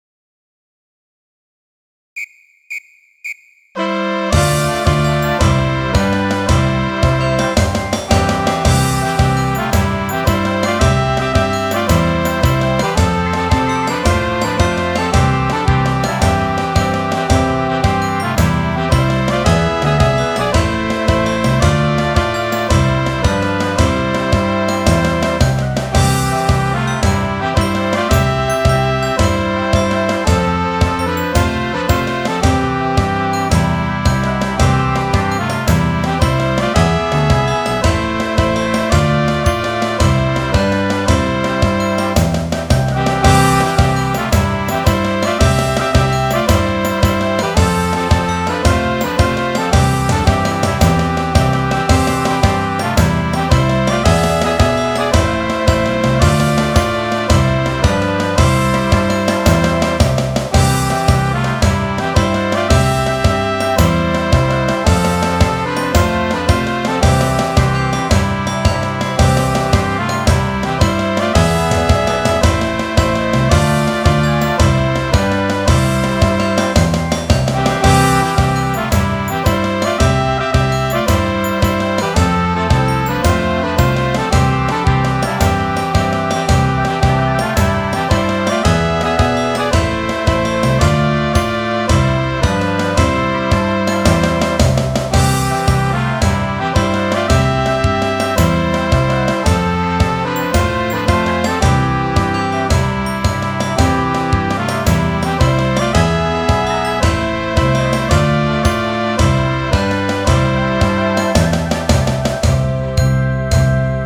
Midi File, Lyrics and Information to Battle Hymn of the Republic/John Brown's Body